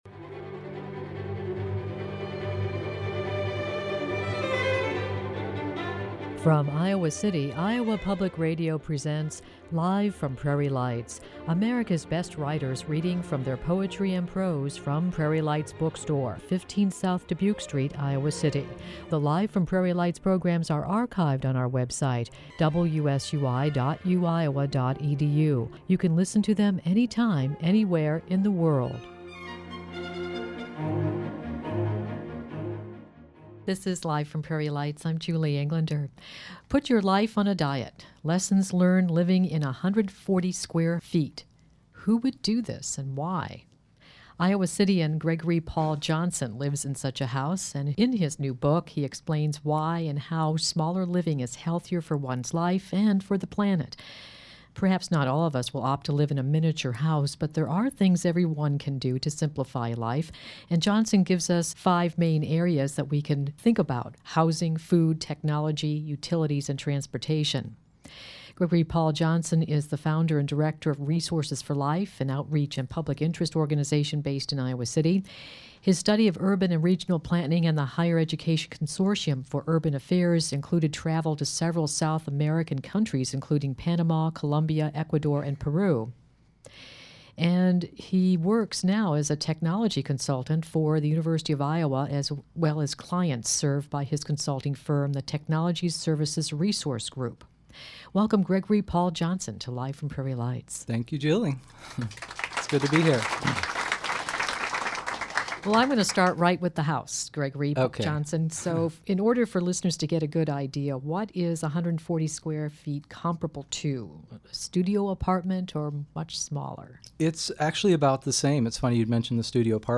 Radio Interviews